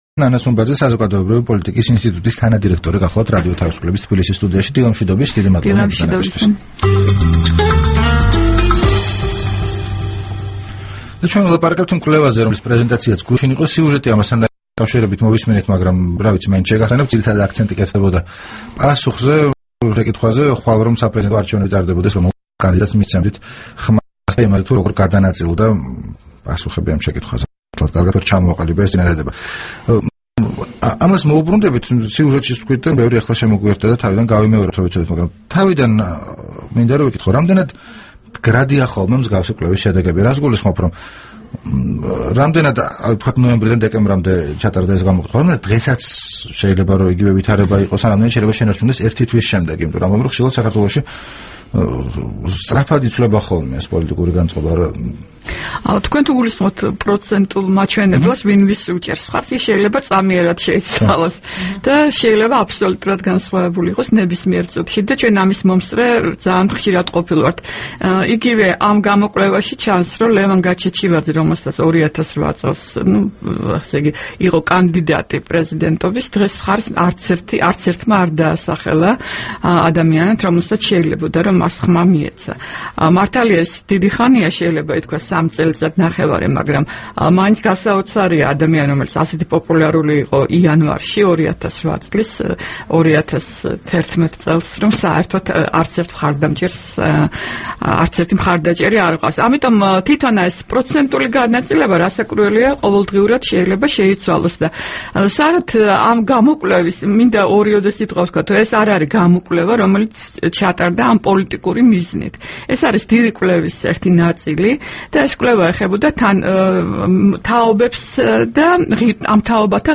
საუბარი